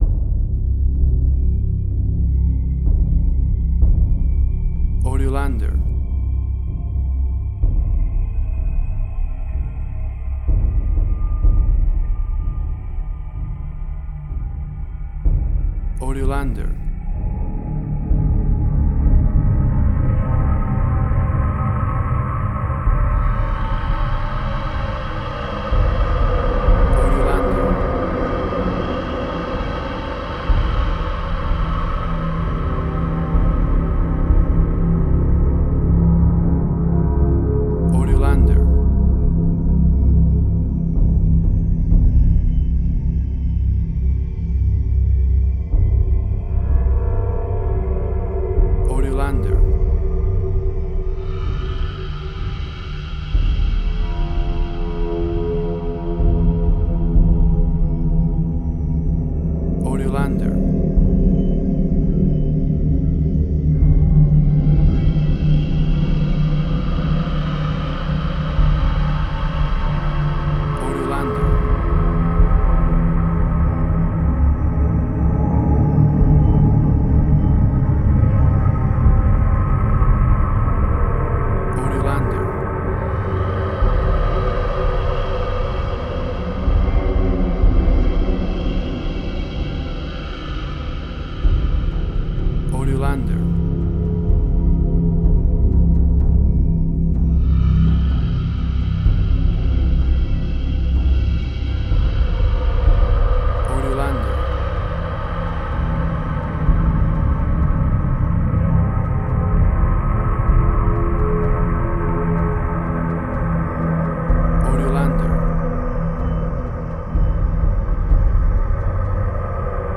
Suspense, Drama, Quirky, Emotional.
Tempo (BPM): 64